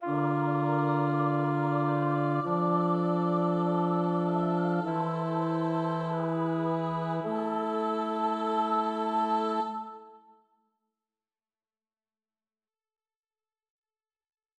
자코모 카리시미는 오라토리오 ''입다''에서 끔찍한 운명에 직면한 입다의 딸과 동료들의 슬픔("lachrimate")을 묘사했다.[8] 리처드 타루스킨은 "딸의 비탄은... 종지에서 '프리지아'의 낮춰진 둘째 음계를 특히 효과적으로 사용하여 나중에 나폴리 6화음으로 불리게 될 화음을 만들어낸다."라고 설명한다.[8]